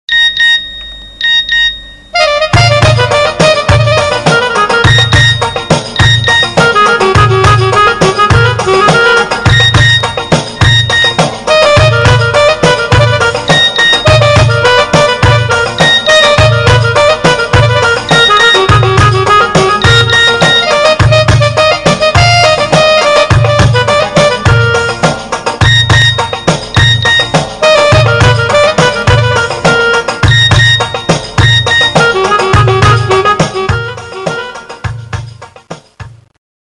Арабские мотивы